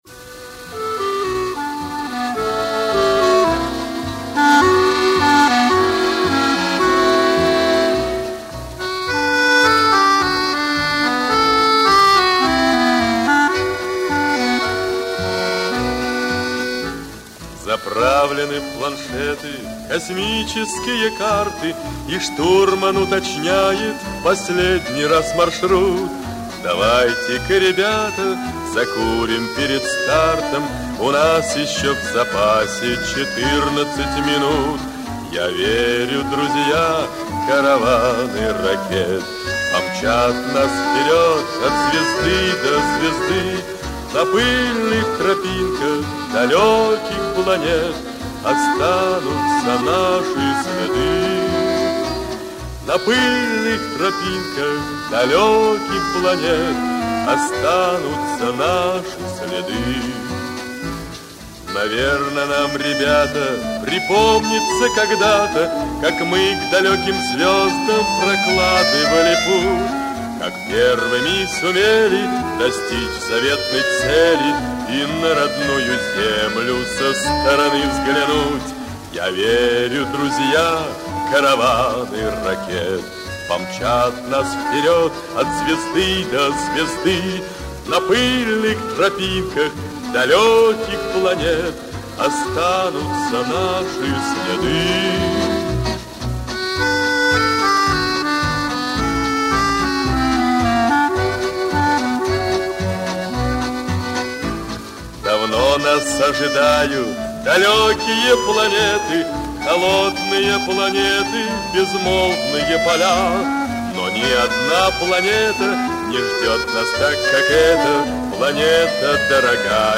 ранний вариант с еще не отредактированным текстом